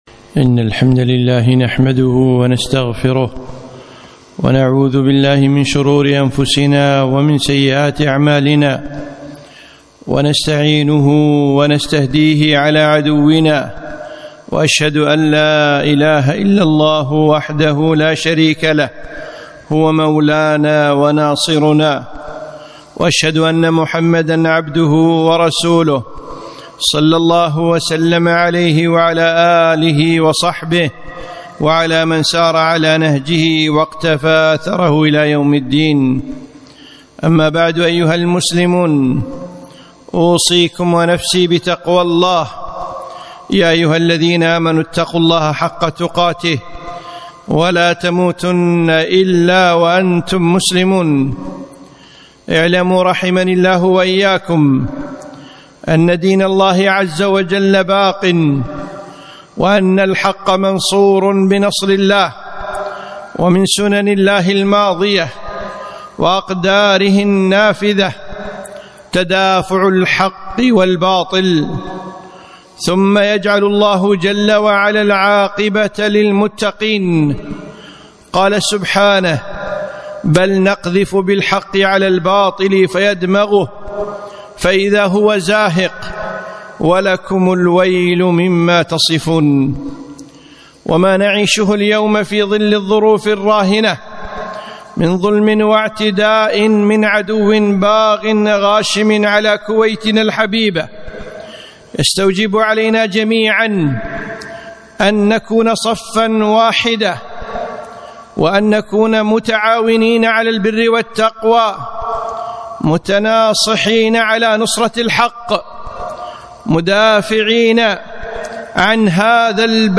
خطبة - أبشروا يا جنودنا المرابطين